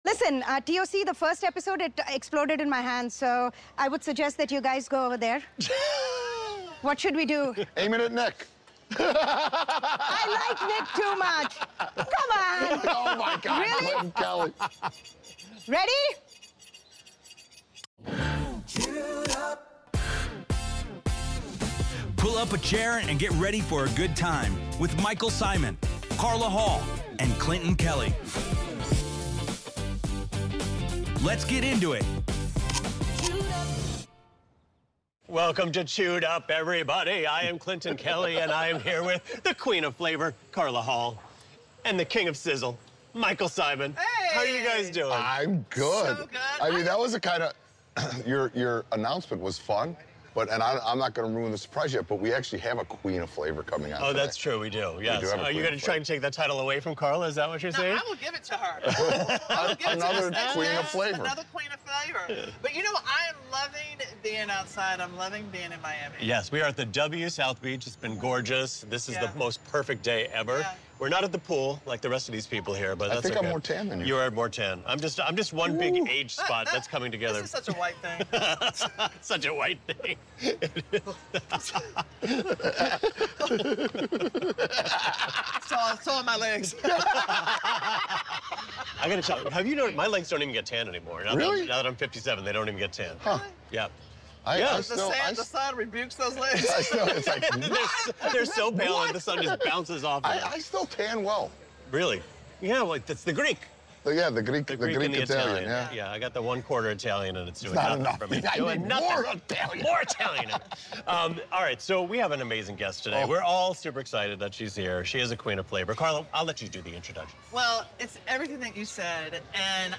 This episode of Chewed Up brings bold flavor, vibrant energy, and global inspiration as Michael Symon, Carla Hall, and Clinton Kelly welcome the one and only Maneet Chauhan in Miami at the W South Beach.